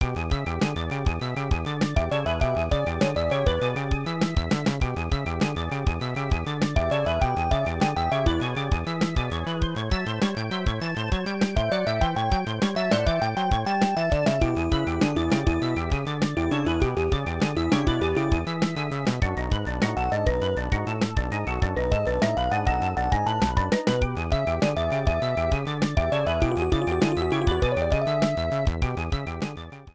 Minigame music